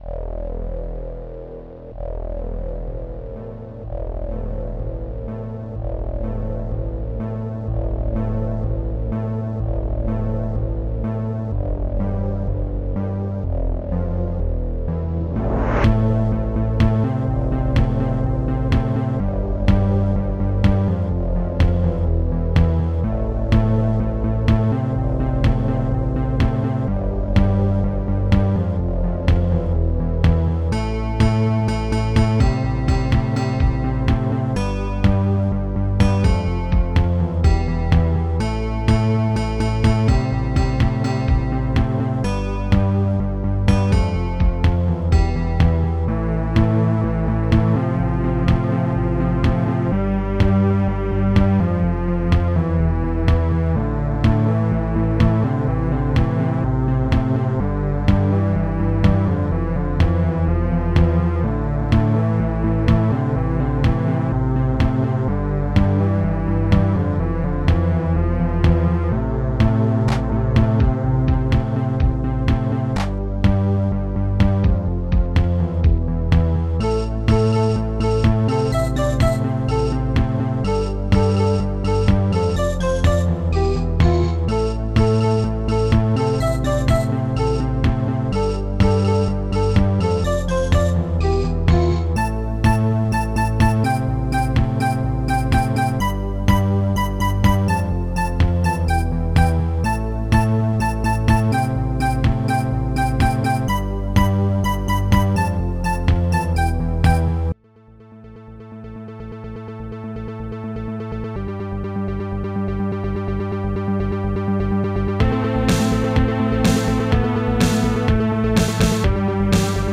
Protracker and family
st-97:synth8
st-98:arztbass
st-96:spacioussweep
st-94:akaisnare
st-94:akaikick
st-73:awcpiano1
st-01:strings7